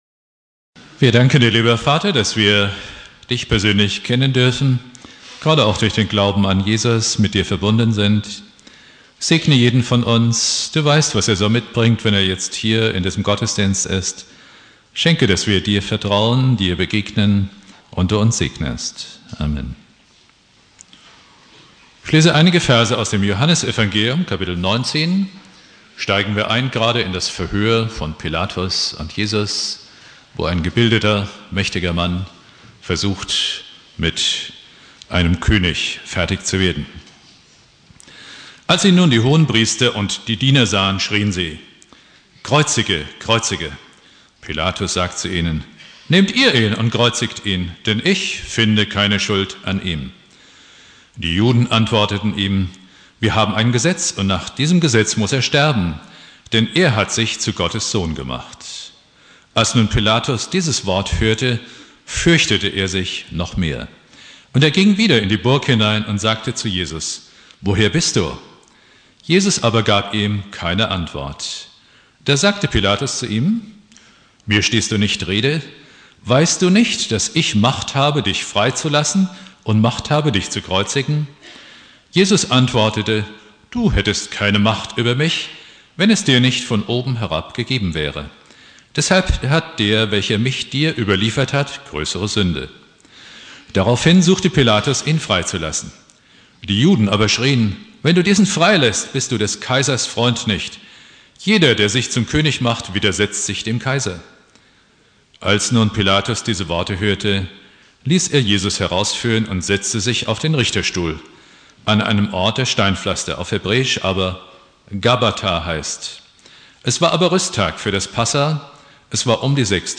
Predigt
Karfreitag